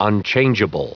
Prononciation du mot unchangeable en anglais (fichier audio)
Prononciation du mot : unchangeable